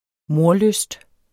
Udtale [ ˈmoɐ̯- ]